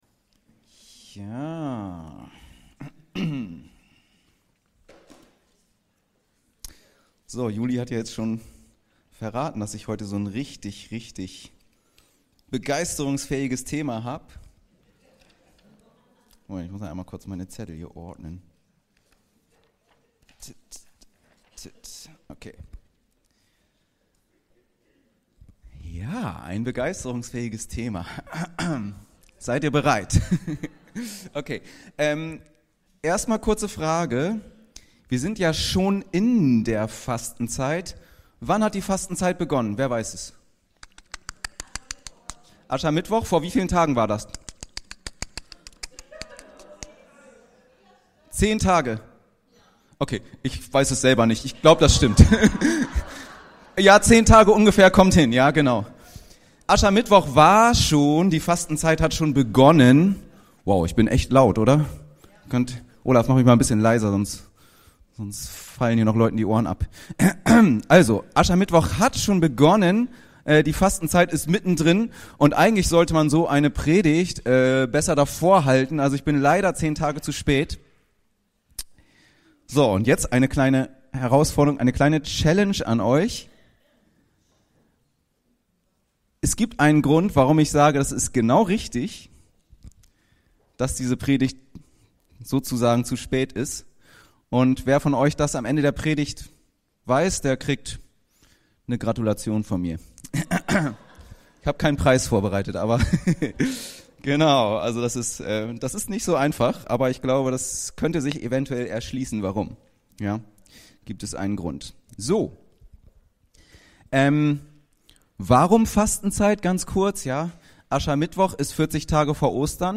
Fasten und Verzicht ~ Anskar-Kirche Hamburg- Predigten Podcast